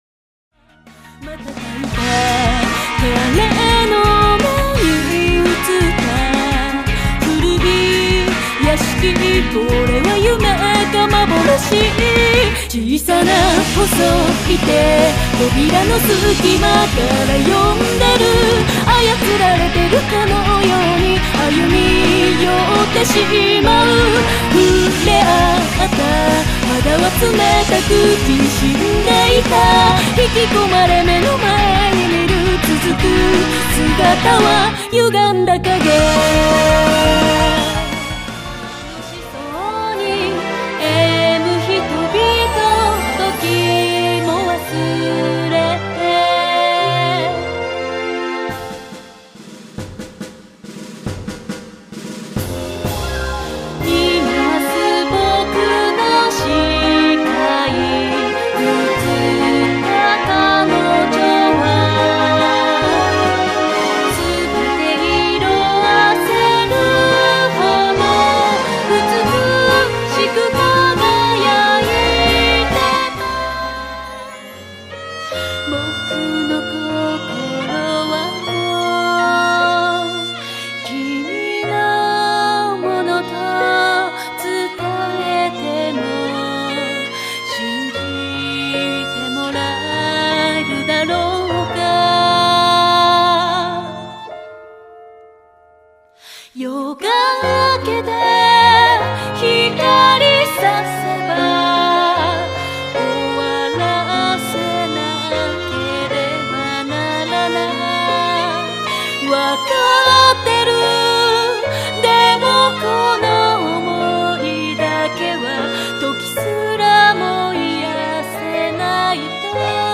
全曲クロスフェードデモ（5'18"/4.85MB）
仕様 ：全8曲オリジナルフルボーカルアルバム
violin